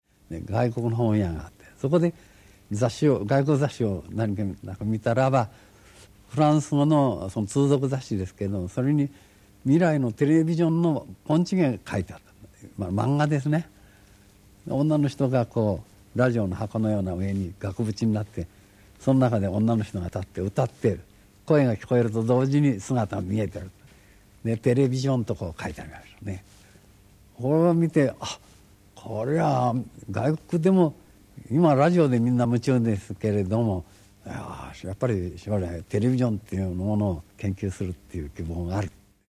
高柳健次郎本人が語ります。
〔NHK教育テレビ「私の自叙伝 〜テレビ事始め〜」1980年3月13日放送より〕